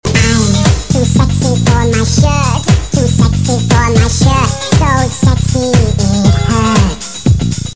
• Animal Ringtones